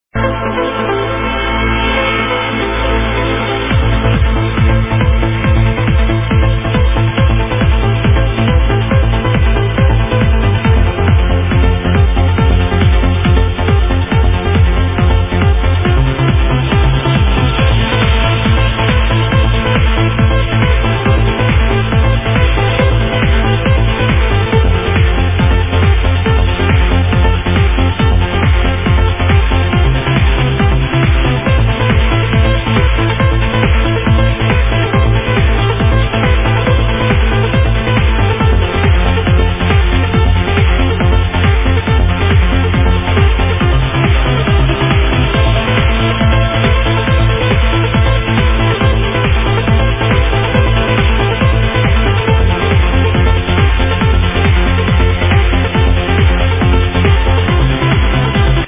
Progressive Trance Track